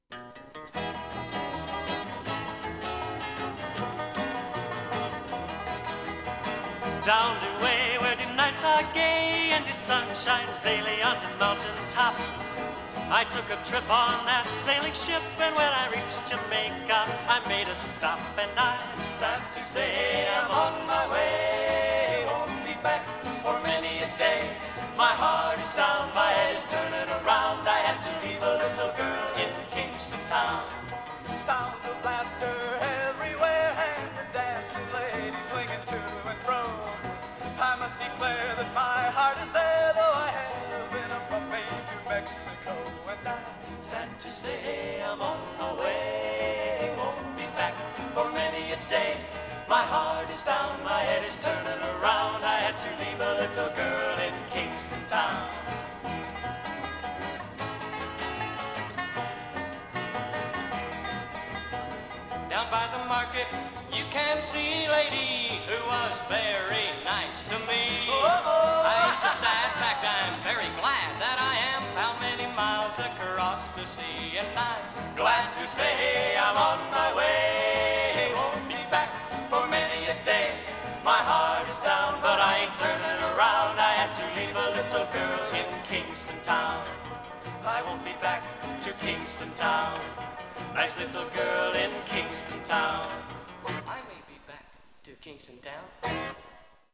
Genre: Traditional | Type: Specialty |Studio Recording